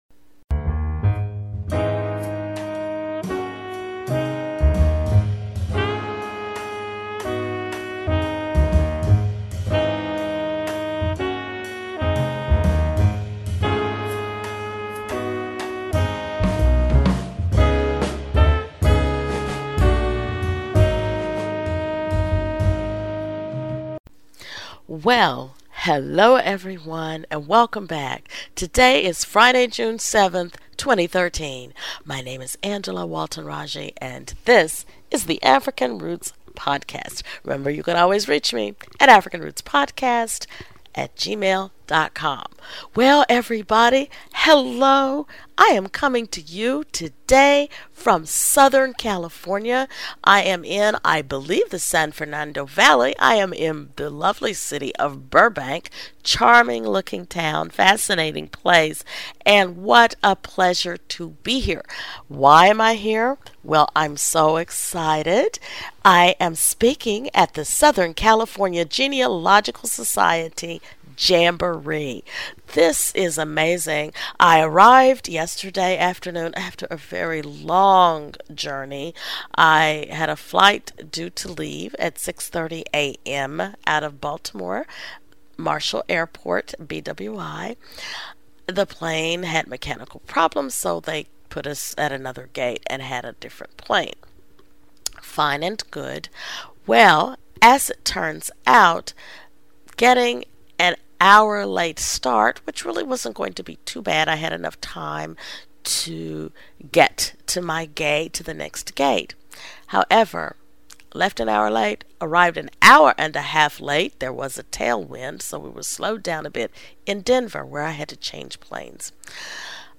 I am coming to you today from Burbank California, where I am attending the Southern California Genealogy Society Genealogy Jamboree!